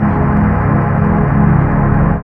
1807L SYNPAD.wav